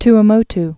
(tə-mōt)